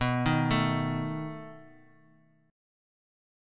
B7 Chord
Listen to B7 strummed